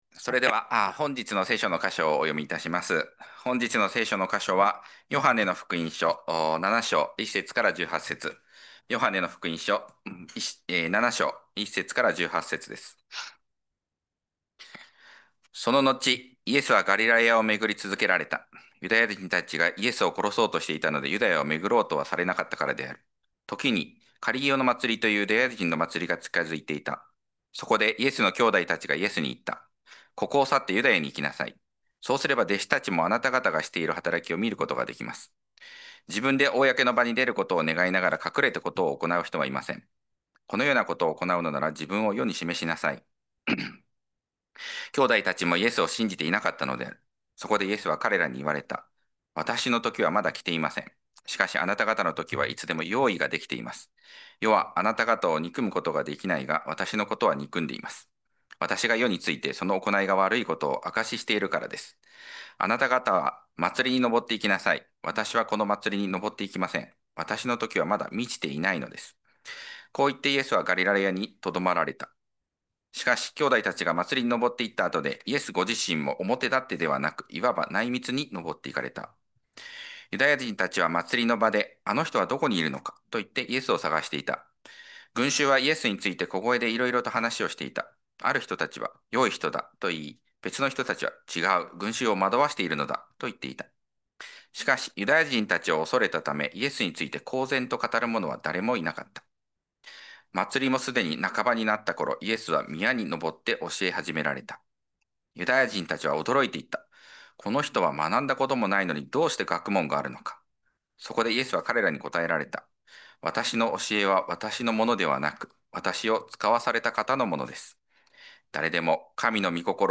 2025年10月19日礼拝 説教 「人を非常に恐れたため」 – 海浜幕張めぐみ教会 – Kaihin Makuhari Grace Church